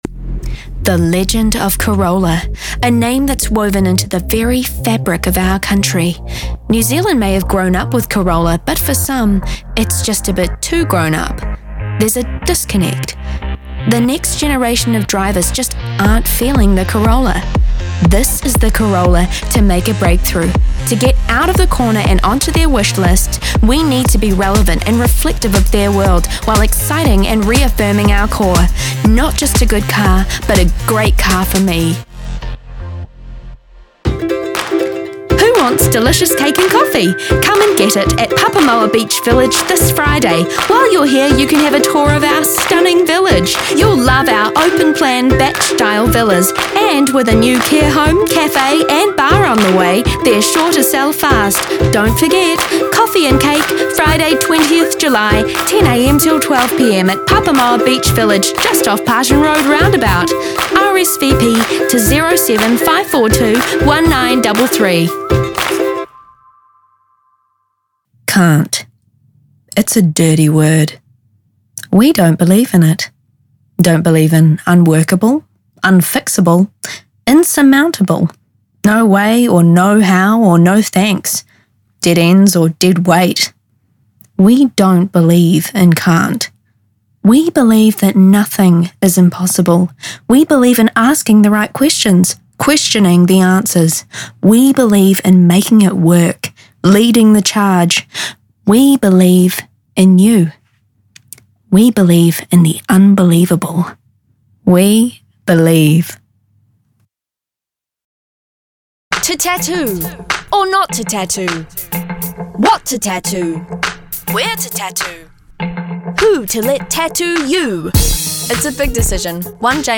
Demo
Young Adult
new zealand | natural
COMMERCIAL 💸
comedy